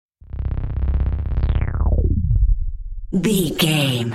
Sound Effects
Atonal
magical
mystical
special sound effects